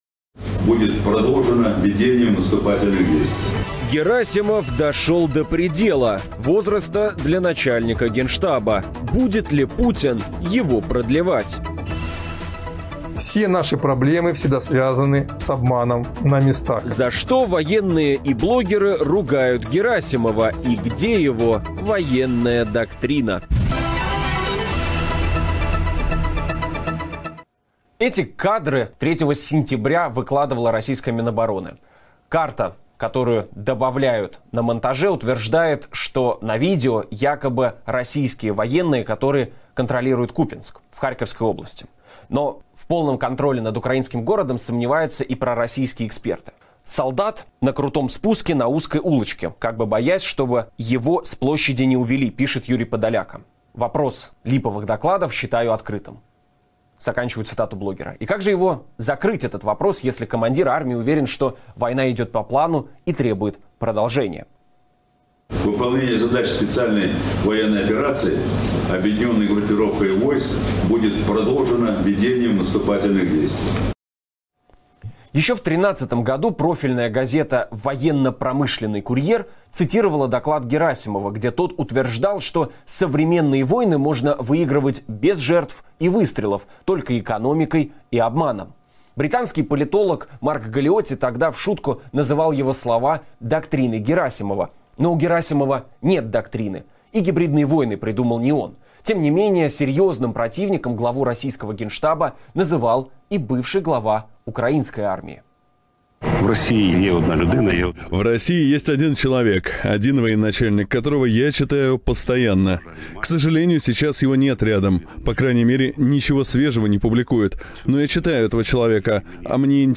В чем секрет непотопляемости Герасимова и задумываются ли в Кремле о смене главы генштаба? Обсуждаем в эфире программы "Лицом к событию" с военным аналитиком